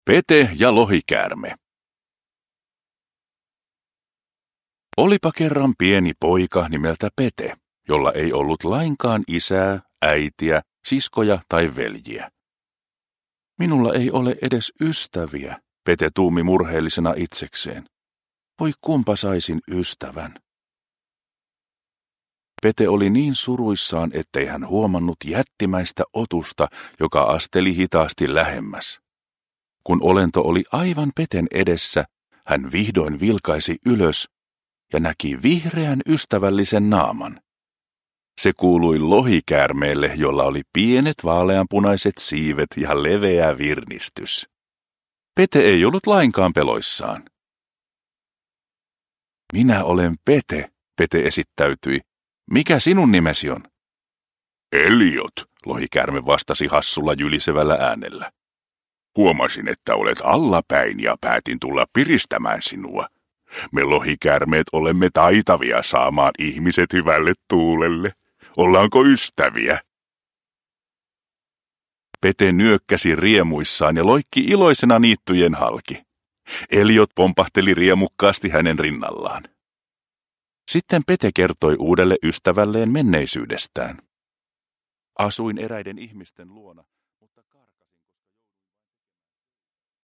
Pete ja lohikäärme – Ljudbok – Laddas ner